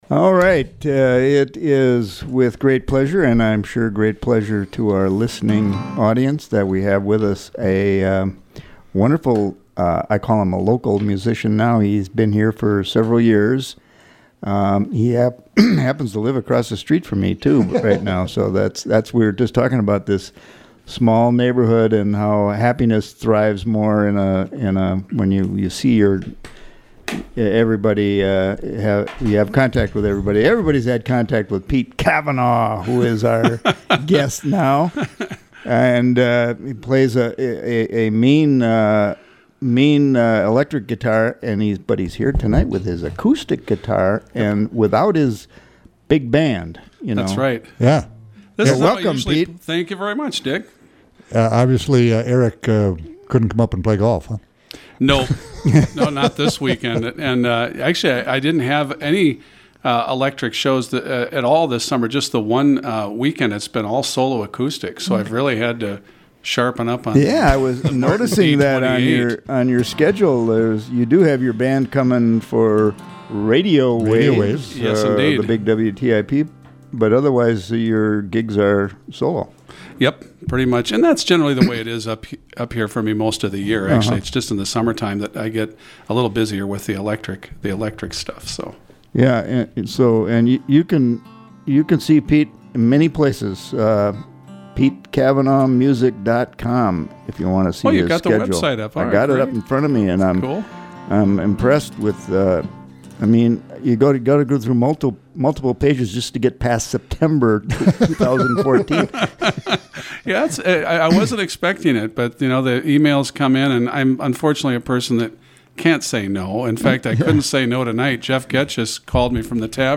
acoustic set
Program: Live Music Archive The Roadhouse